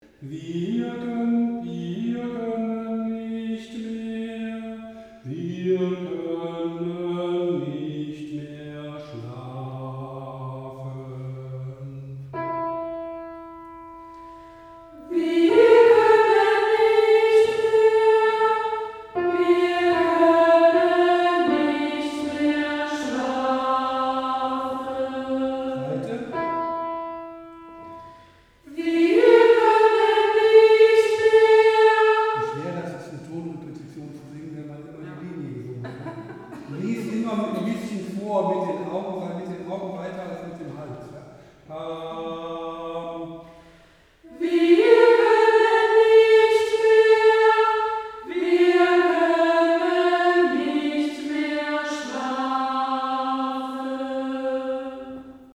Takt 35 - 42 | Einzelstimmen
Gott in uns! | T 35 | Alt 1